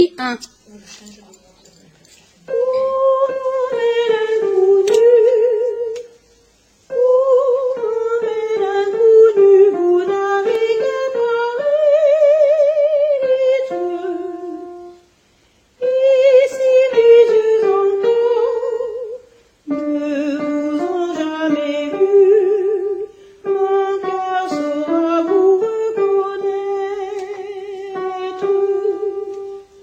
alti 1 debut